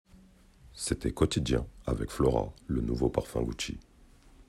Voix off
Son pub
Voix 20 - 34 ans - Basse